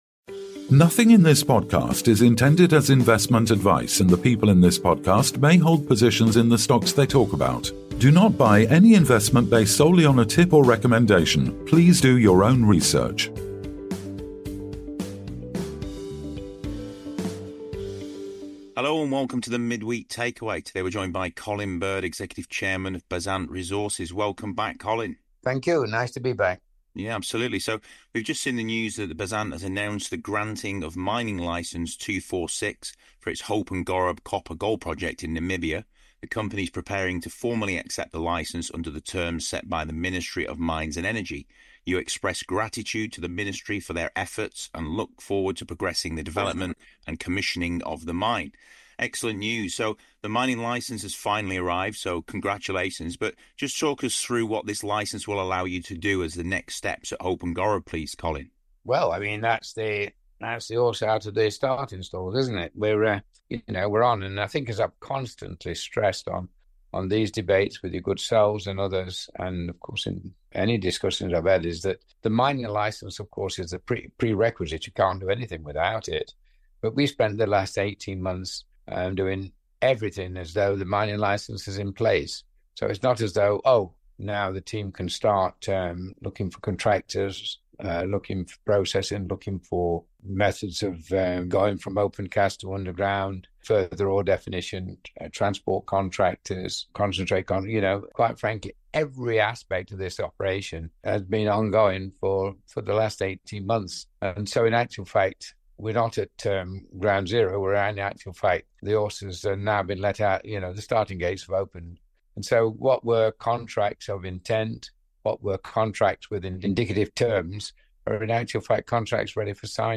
Tune in for an insightful conversation about the future of this key project and its potential impact on the company and the region.